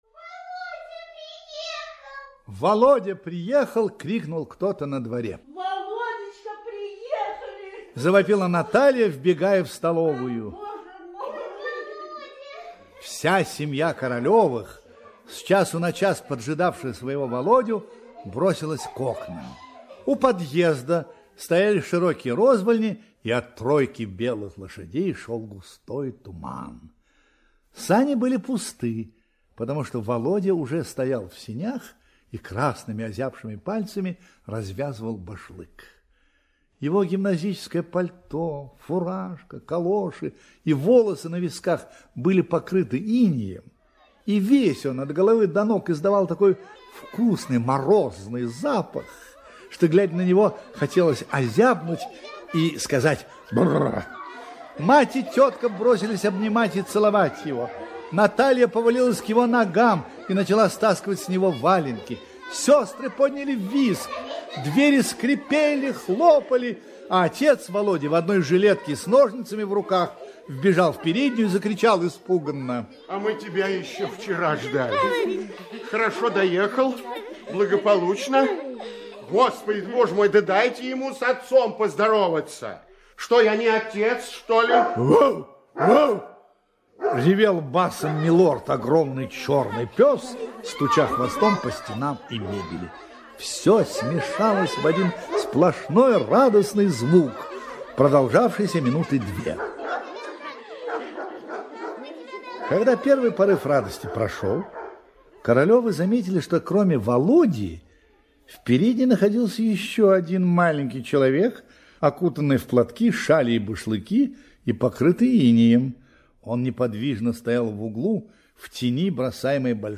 Мальчики - Чехов - слушать рассказ онлайн